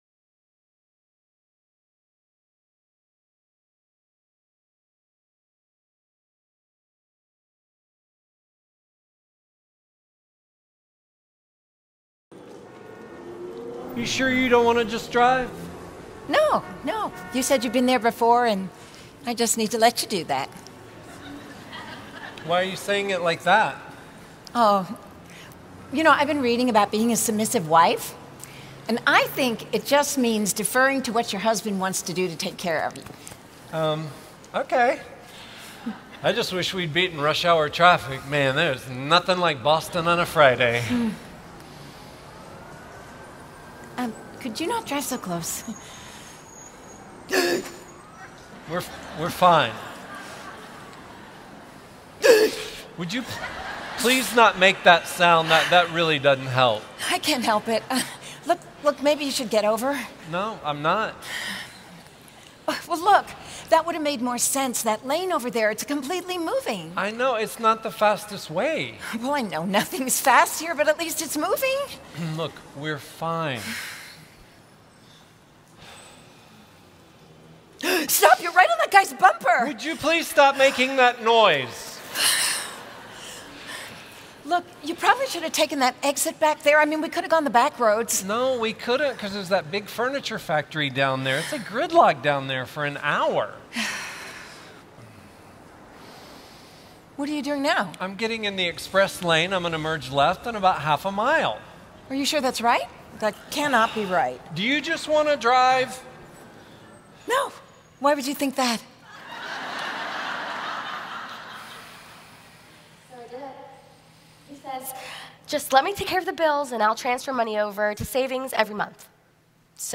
Message 12 (Drama): Submit or Resist
Revive '17 Adorned Conference - DVD Set